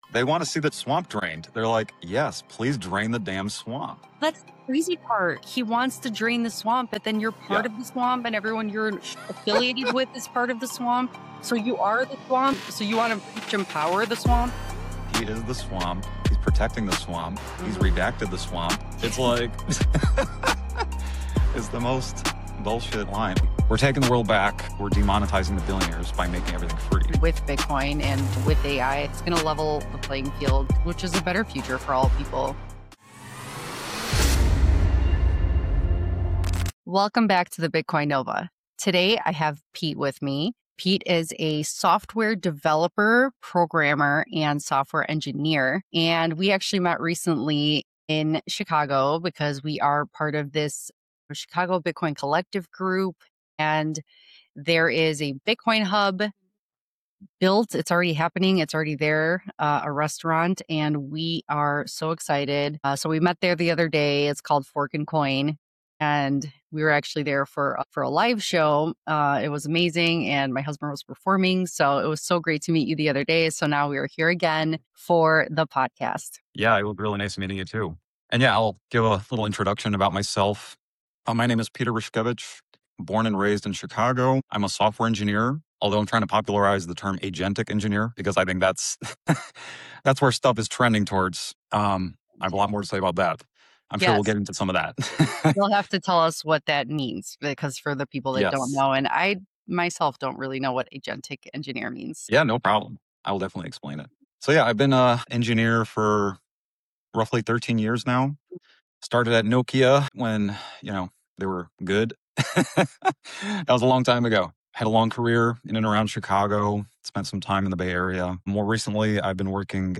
This is a conversation about building, not just complaining.